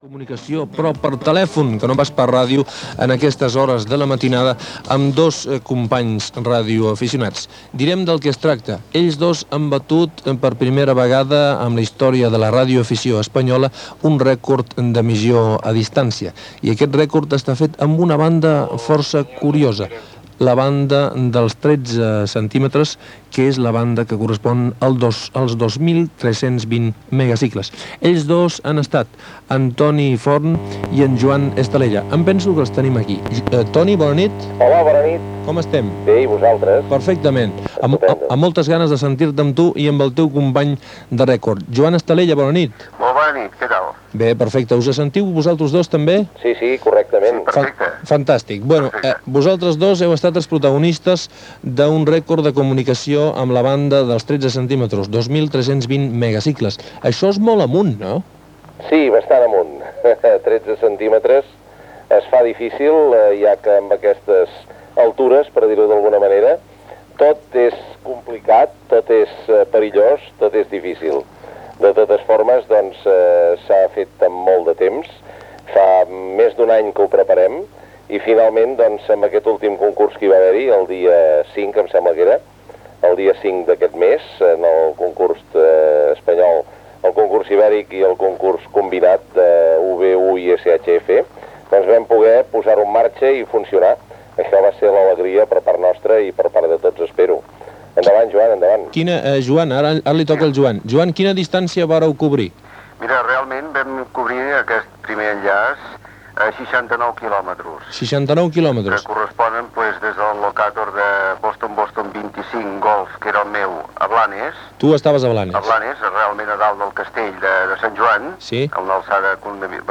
cf1ae38b3591d909bc32da5a193118ff78bef0f2.mp3 Títol Ràdio 4 Emissora Ràdio 4 Cadena RNE Titularitat Pública estatal Nom programa L'altra ràdio Descripció Rècord de distància en una emissió a la banda de radioaficionats de 13 cm. Entrevista als radioaficionats que el van fer possible el dia 5 de maig de 1984